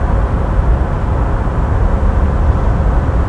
AMBIENT
MACHHUM.WAV